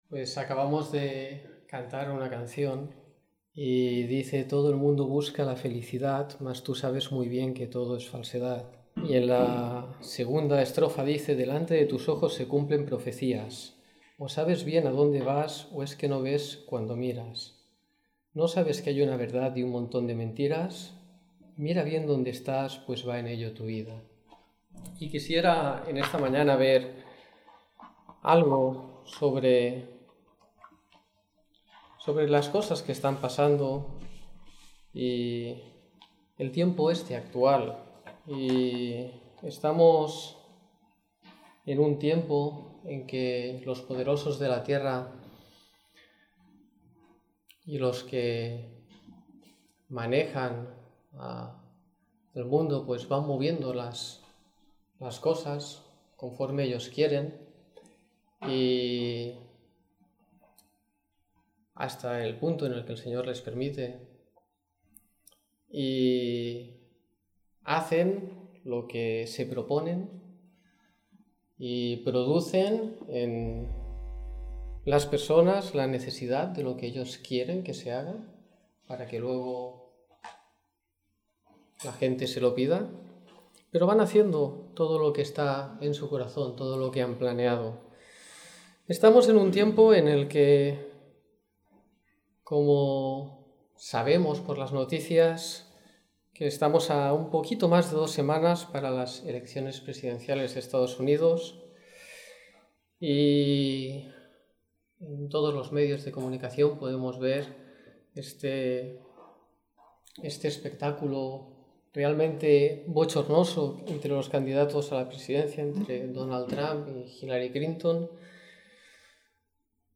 Domingo por la Mañana